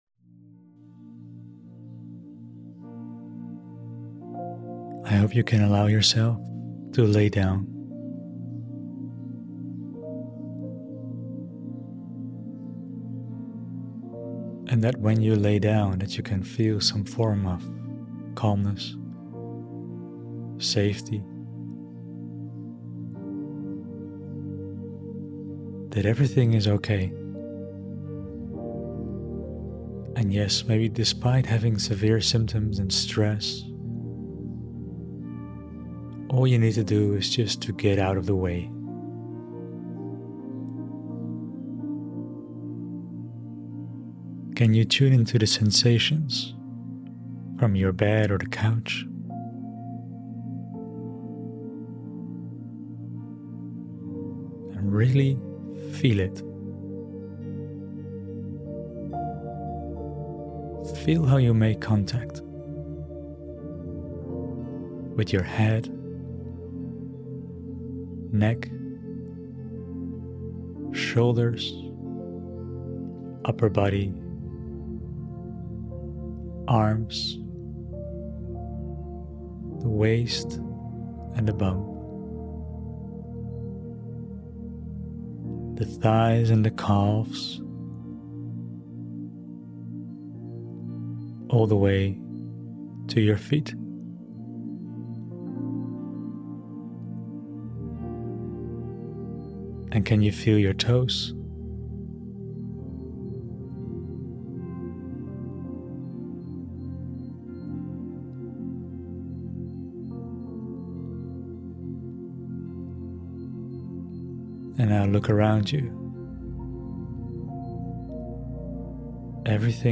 🌿 Safety Meditation: Calm Your Nervous System with Gentle Breathing and EMDR Elements 🌿
In this guided meditation, I invite you to embark on a journey towards tranquility and safety. Designed to calm the nervous system, this meditation blends soothing breathing techniques with elements of EMDR to help process feelings of safety and release tension.